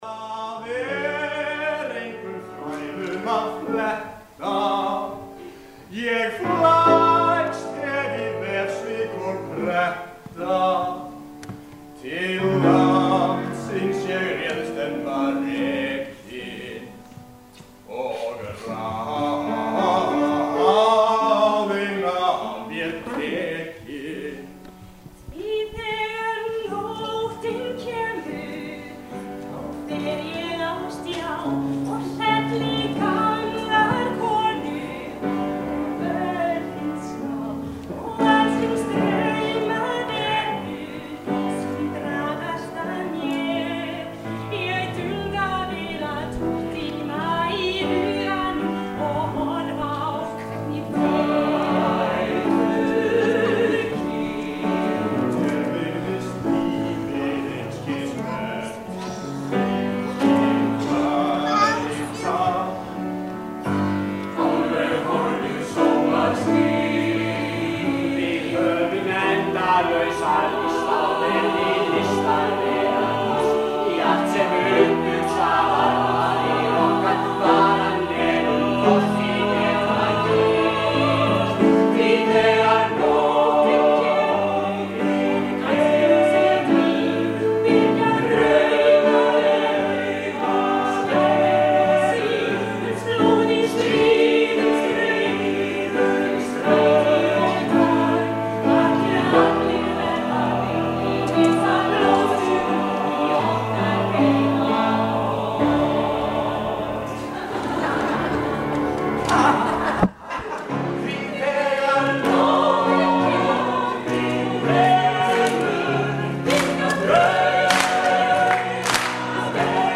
Tónlistarflutningur